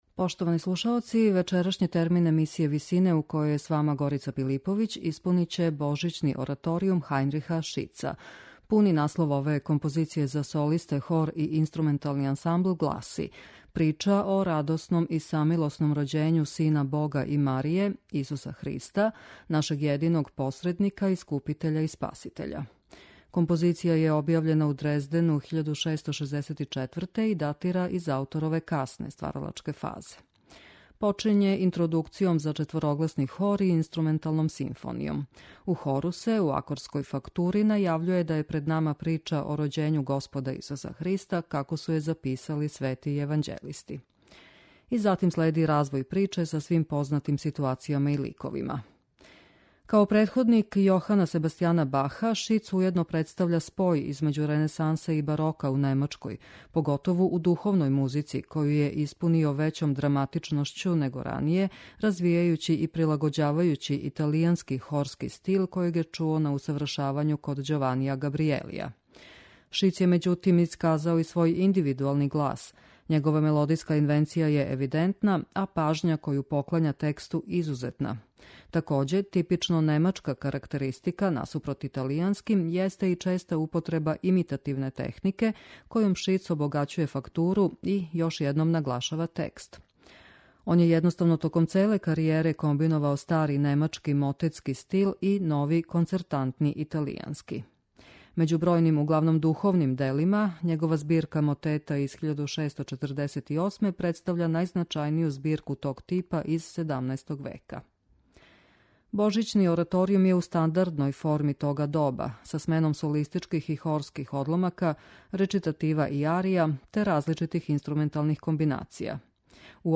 медитативне и духовне композиције
ранобарокног